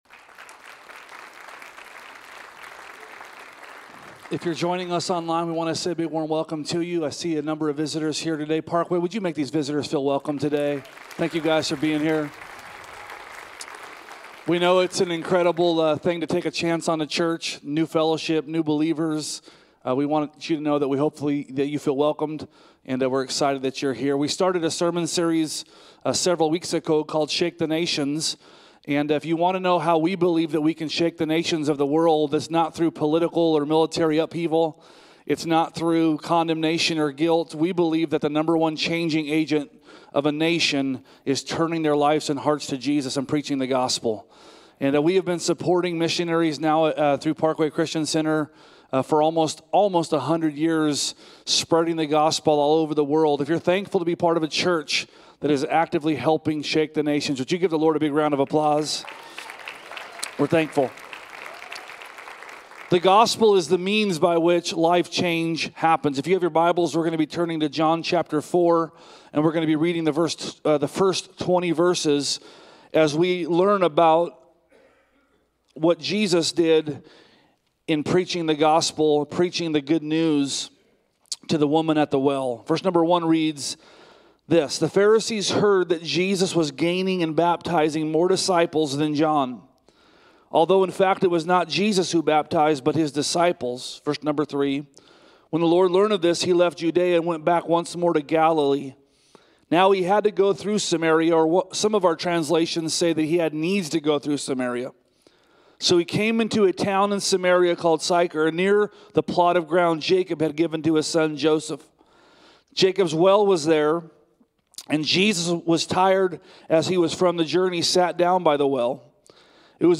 A message from the series "Shake the Nations."